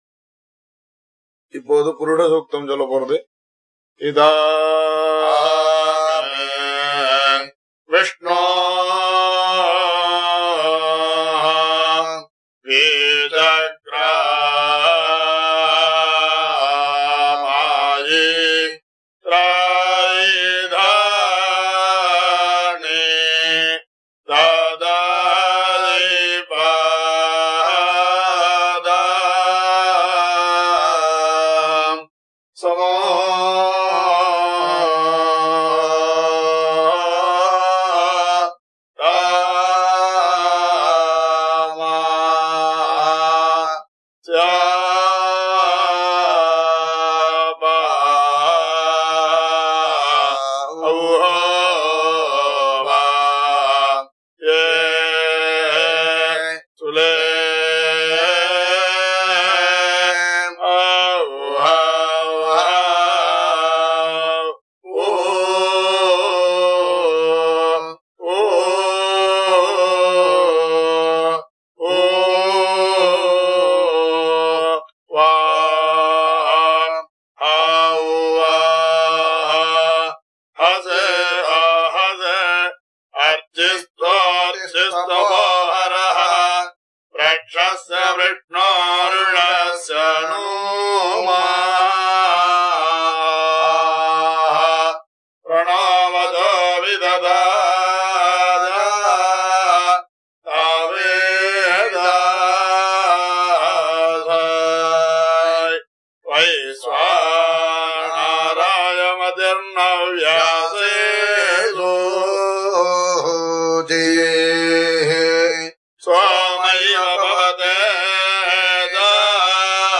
Srimate Rangaramanuja Mahadesikaya Namaha I. On the Purusha Suktam of the sAma vEdam The purusha suktam of the Sama Vedam, as sung traditionally, is a compilation of the following sAman-s in this order: 1. vishnu sAman, found in the Eka-sAmi aindra-parvam.
The last of the four varAha sAmans is sung here.